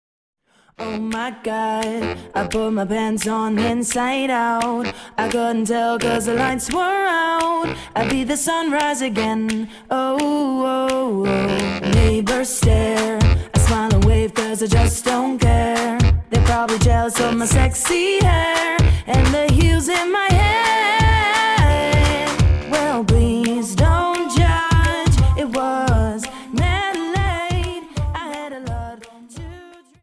Dance: Jive 43 Song